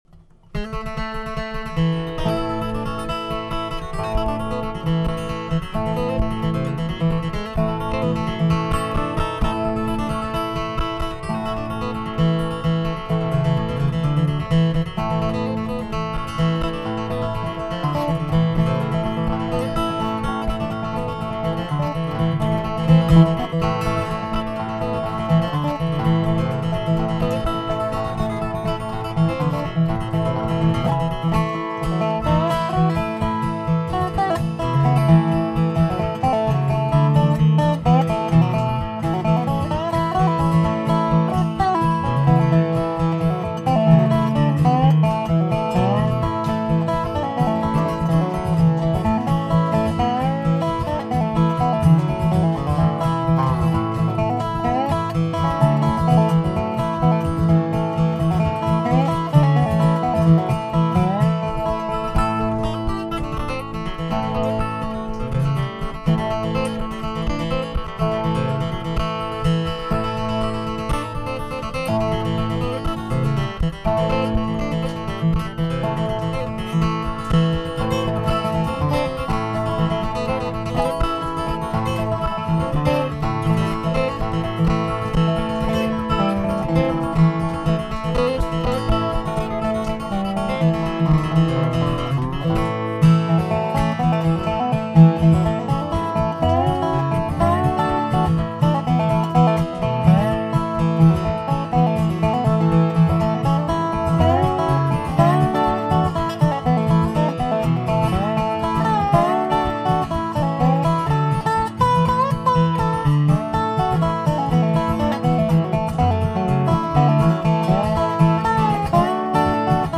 Guitar, Dobro, Fiddle, Bass